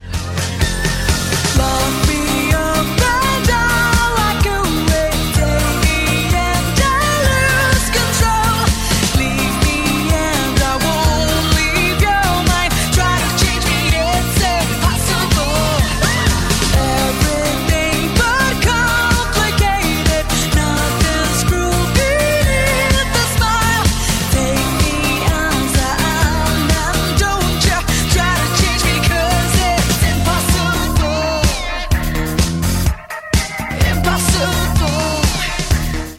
• Качество: 192, Stereo
красивые
женский вокал